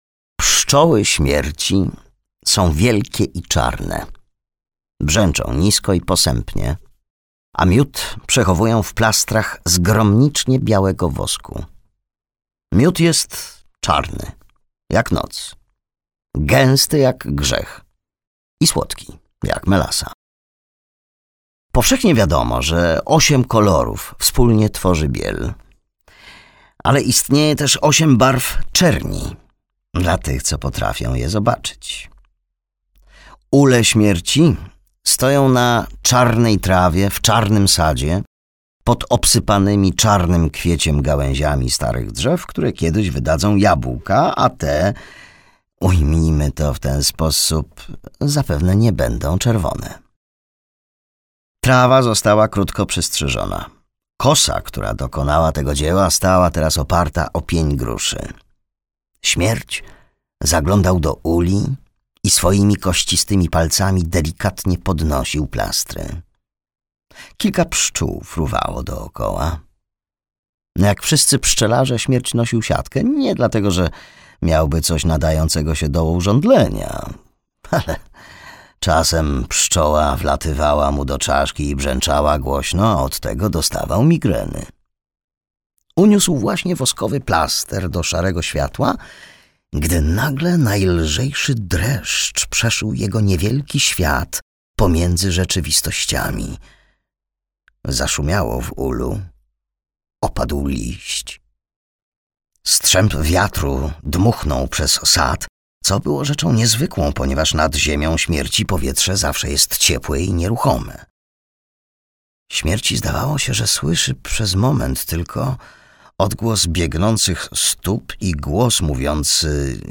Eryk - Terry Pratchett - audiobook